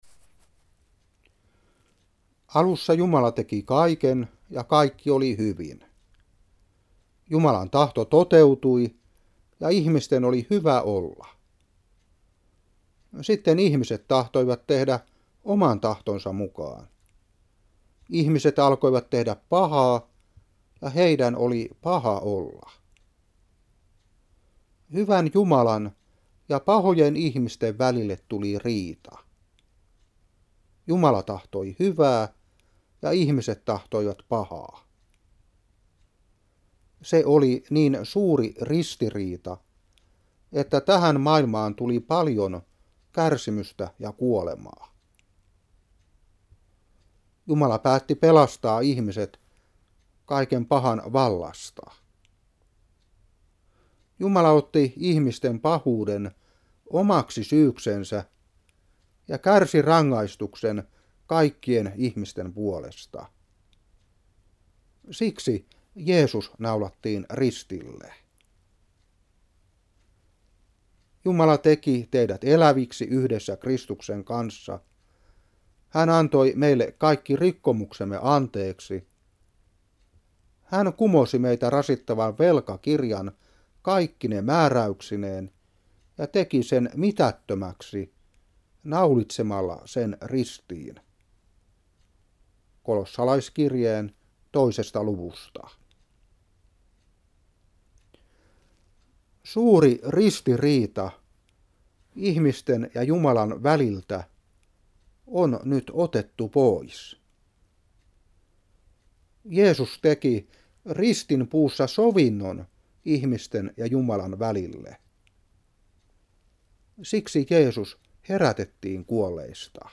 Seurapuhe 2002-3. Kol.2:13-14. Room.4:25.